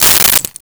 Keys Drop 01
Keys Drop 01.wav